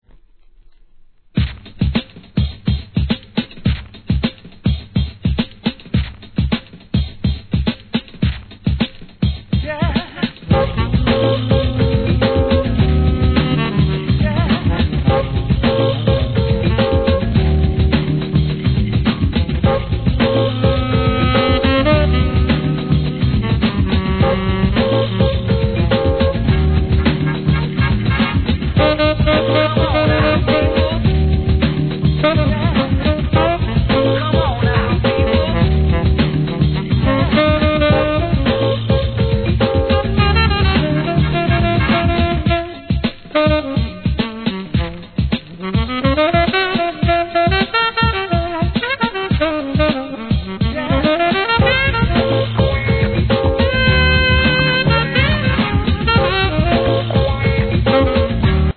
店舗 ただいま品切れ中です お気に入りに追加 1993年、JAZZとHIP HOPの見事な融合コンピレーション!!